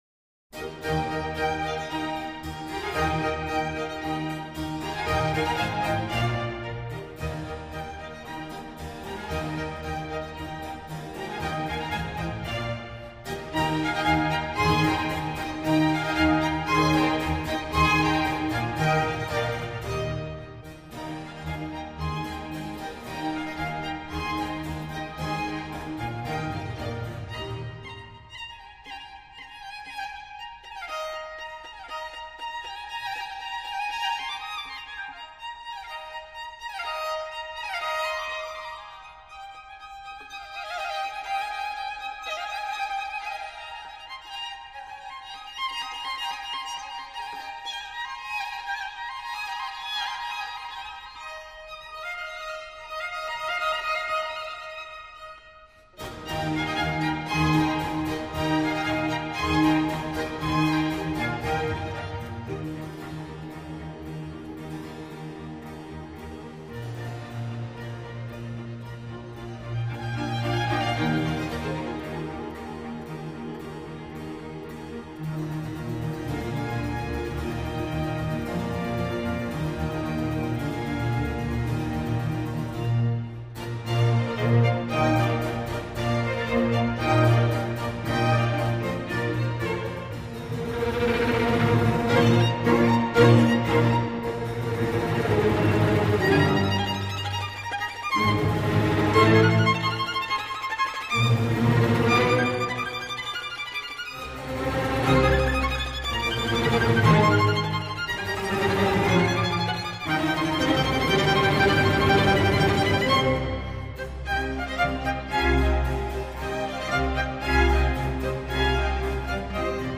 Vivaldi, La primavera, op. 8, n. 1, I tempo — Europa Galante, dir. Fabio Biondi | Opus 111, 2002